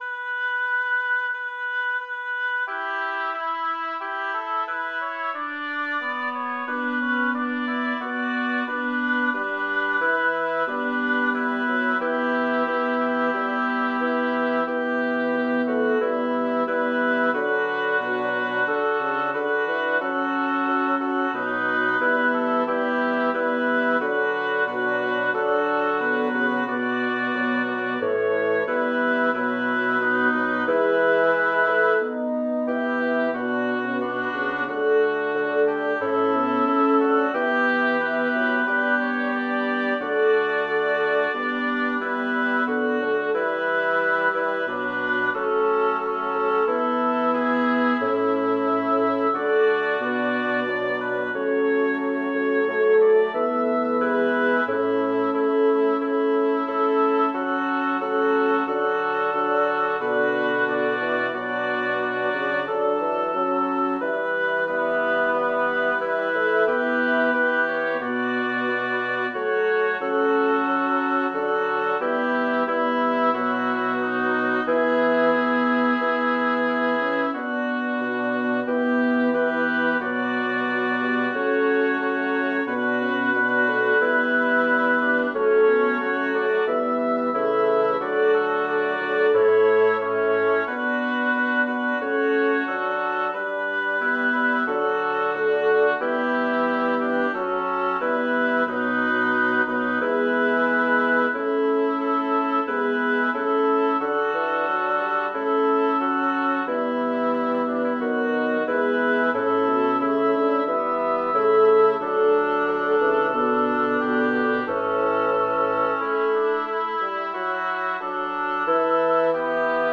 Title: Cum invitatus fueris Composer: Sebastian Homo Lyricist: Number of voices: 5vv Voicing: SATTB Genre: Sacred, Motet
Language: Latin Instruments: A cappella